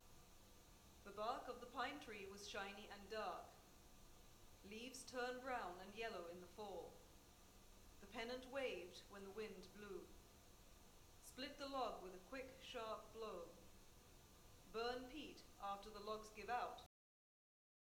How much the headphones attenuate the voice of a person talking to you when background noise is present.
Female Voice 2
female-voice-2-sample.wav